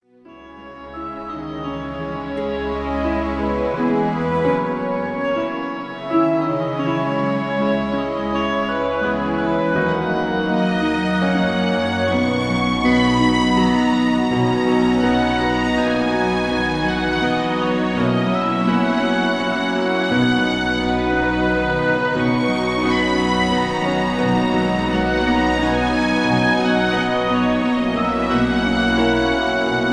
(Key-B) Karaoke MP3 Backing Tracks
Just Plain & Simply "GREAT MUSIC" (No Lyrics).